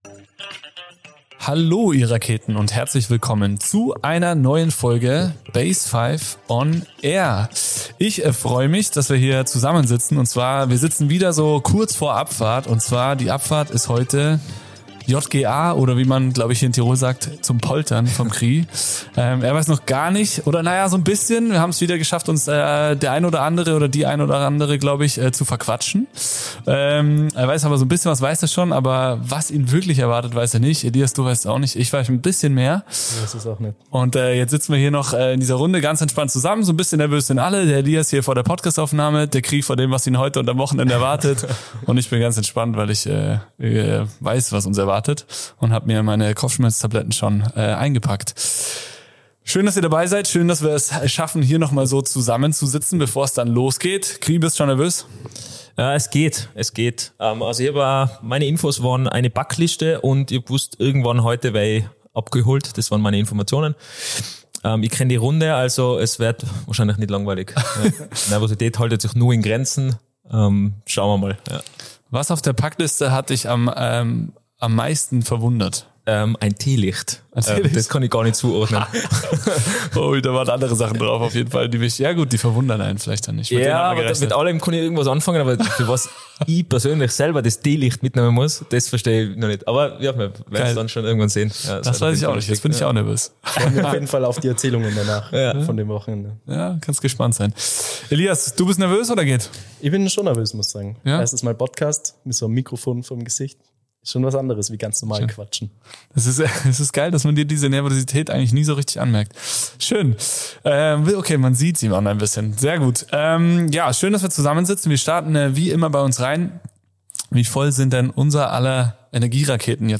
Ein Gespräch für alle, die Orientierung suchen – egal ob als Schüler:in, Elternteil oder Mentor:in.